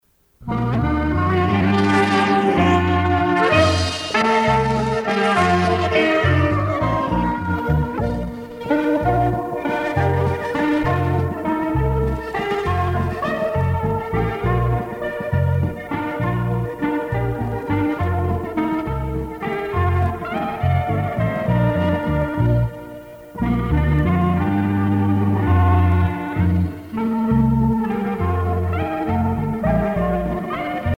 valse musette